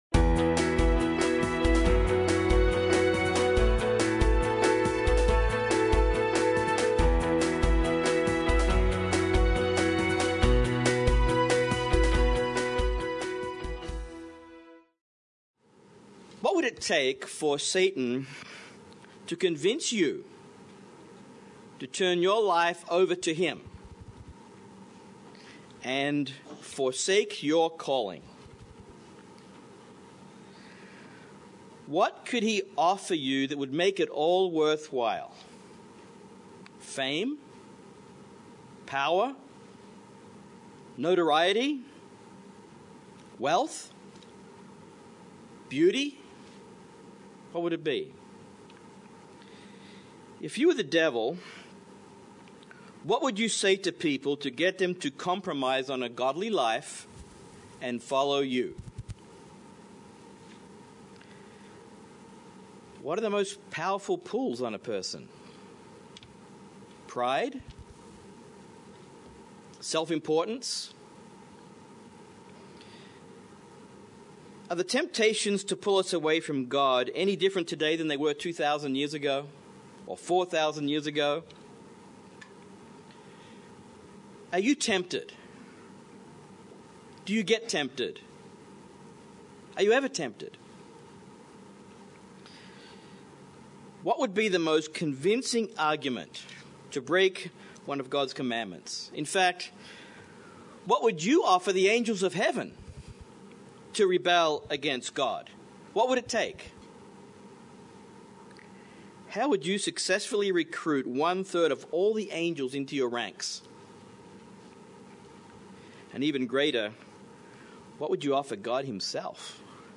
We live in a world of temptation, but we have to remain faithful. In this sermon, we will see 5 ways to beat Satan's temptation and stay close to God.